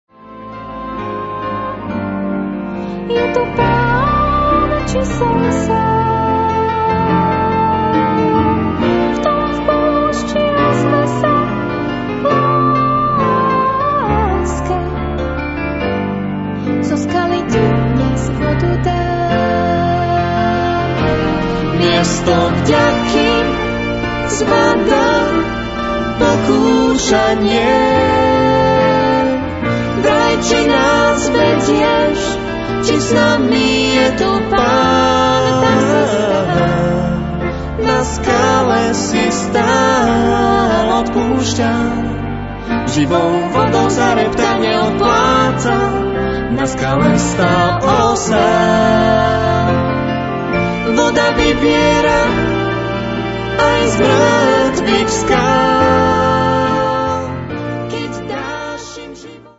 pôvodný slovenský muzikál